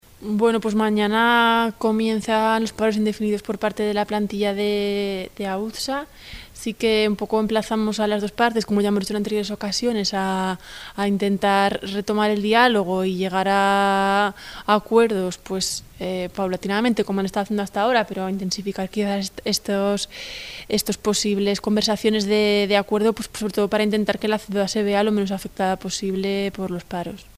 Audio de la concejala Teresa Artigas: Documentos Adjuntos 121509valoracinteresaparosparcialesauzsa.mp3